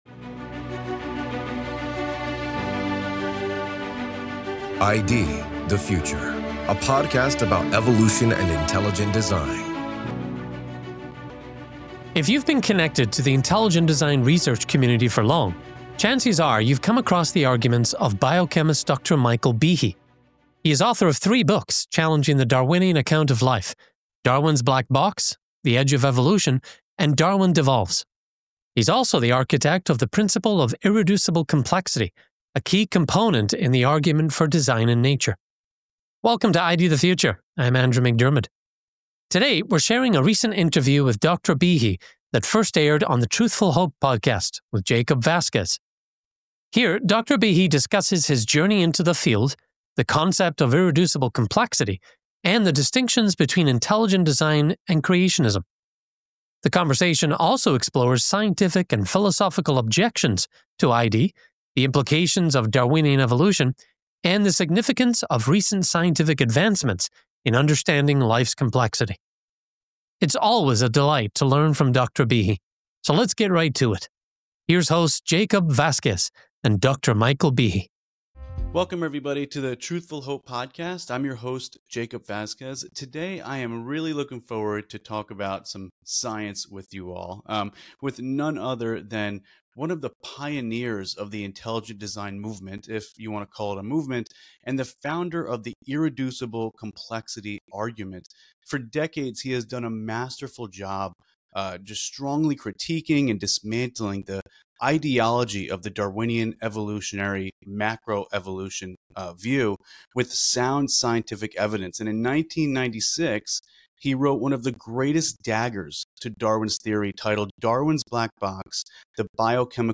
On this ID The Future, we get another chance to learn from biochemist Dr. Michael Behe. Here, Dr. Behe discusses his journey into the field of biochemistry, his key concept of irreducible complexity, and the distinctions between intelligent design and creationism. The conversation also explores scientific and philosophical objections to intelligent design, the implications of Darwinian evolution, and the significance of recent scientific advancements in understanding life's complexity.